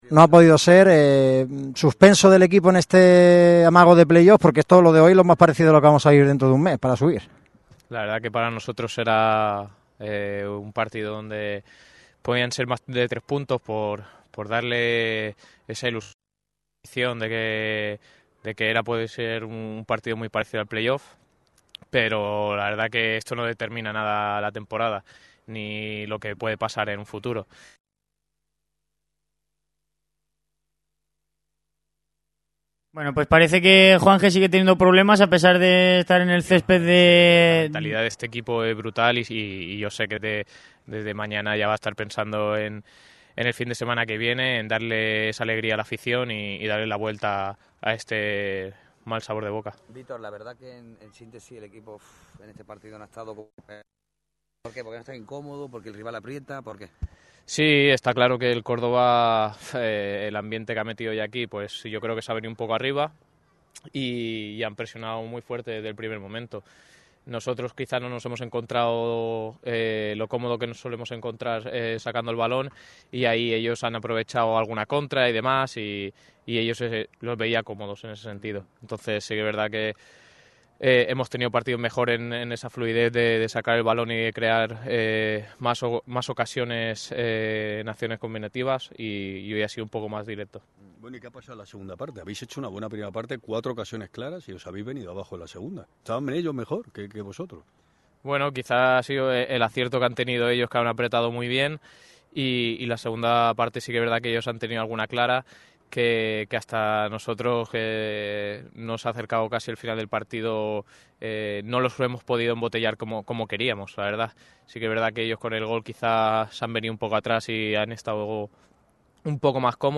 El lateral malaguista ha comparecido ante los medios tras la derrota de los malacitanos en el Nuevo Arcángel ante el Córdoba.